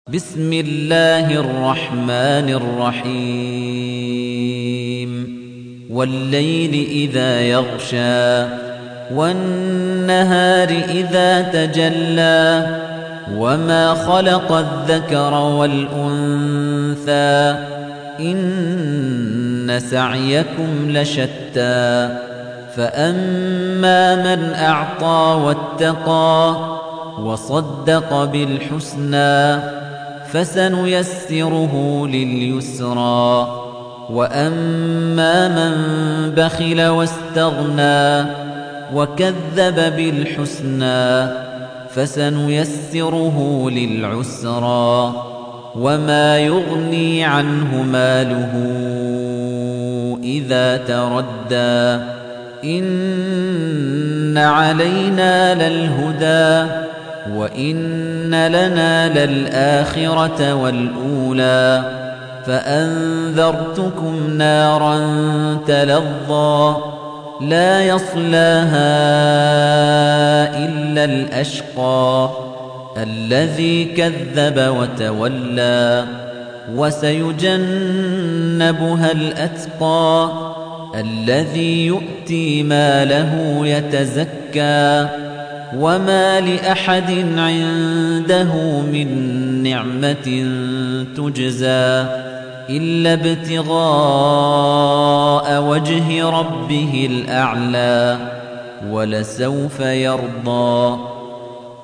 تحميل : 92. سورة الليل / القارئ خليفة الطنيجي / القرآن الكريم / موقع يا حسين